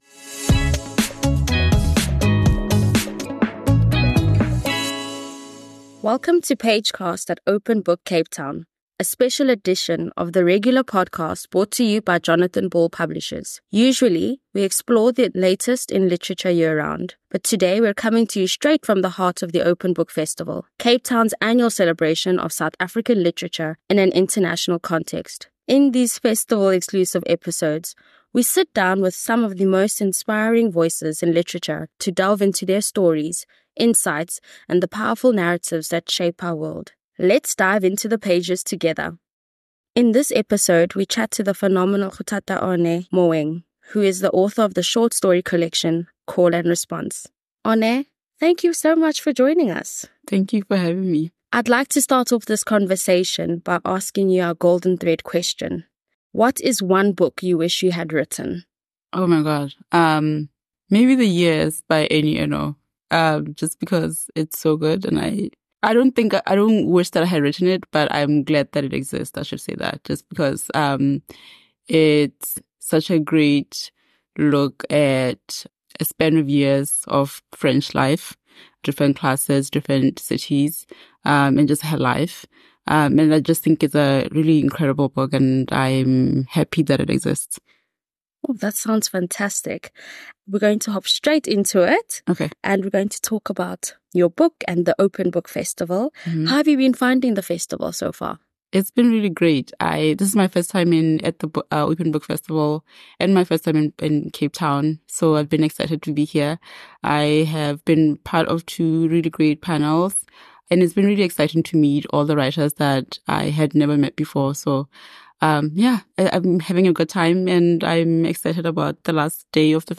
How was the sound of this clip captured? Usually, we explore the latest in literature year-round, but in this episode, we're coming to you straight from the heart of the Open Book Festival—Cape Town's annual celebration of South African literature in an international context.